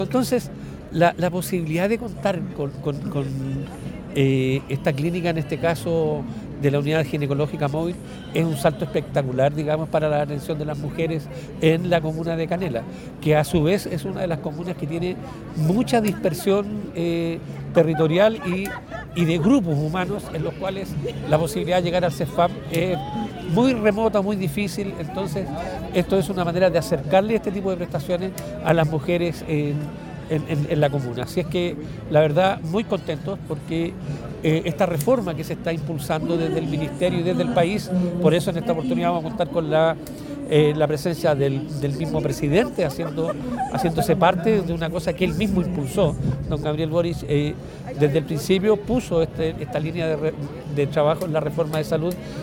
Al respecto, Ernesto Jorquera, director Servicio de Salud Coquimbo, sostuvo que
Ernesto-Jorquera-Director-Servicio-de-Salud-Coquimbo-online-audio-converter.com_.mp3